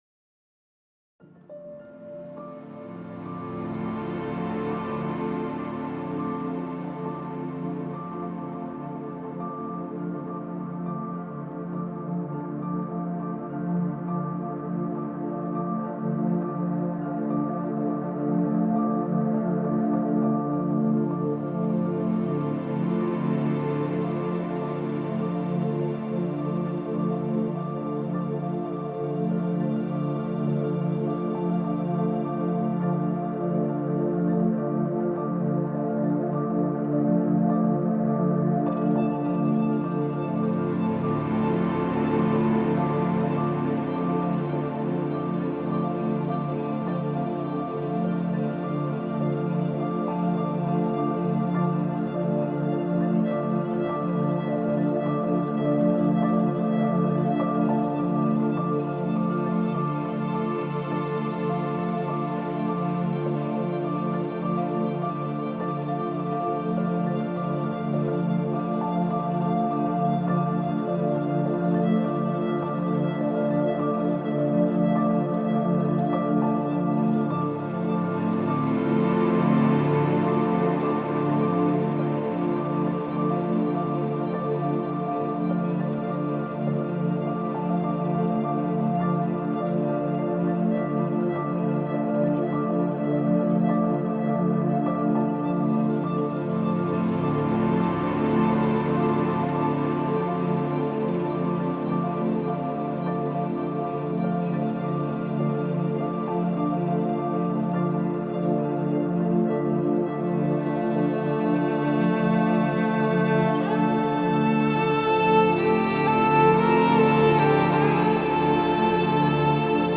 الهام‌بخش , امبینت , پیانو , موسیقی بی کلام , ویولن